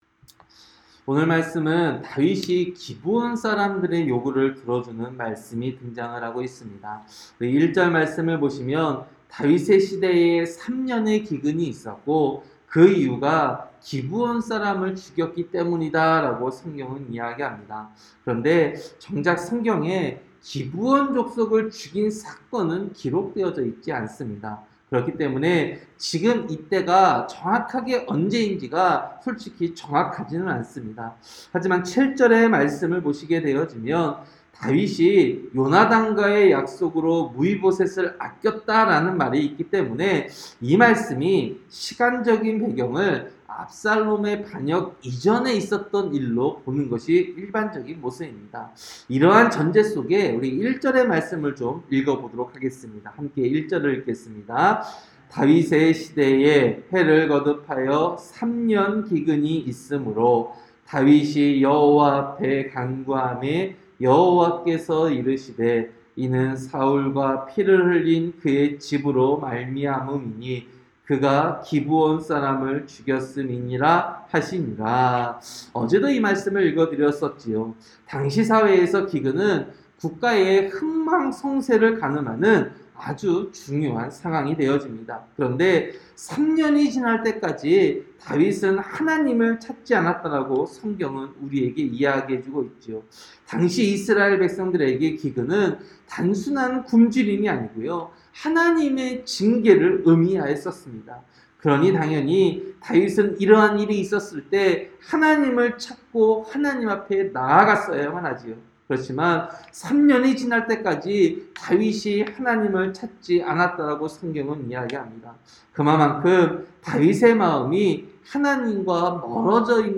새벽설교-사무엘하 21장